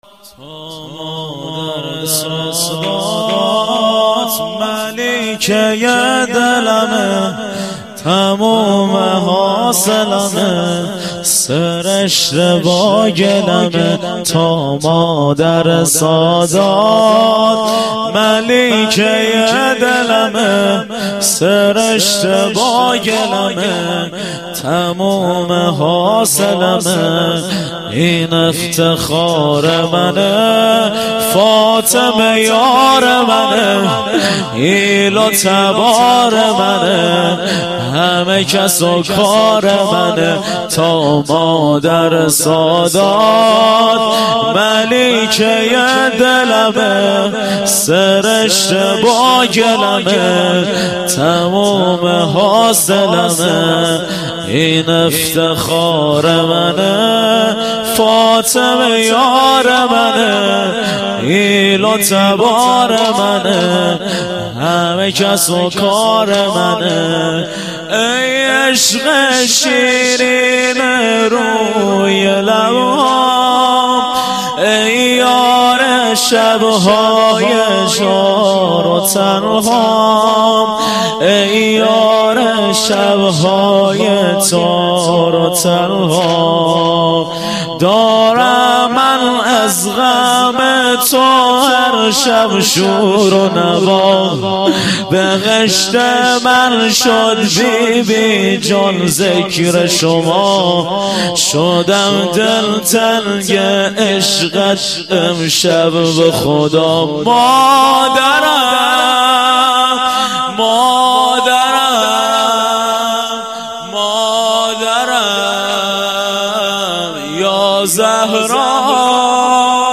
واحد - تا مادر سادات - مداح